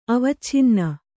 ♪ avacchinna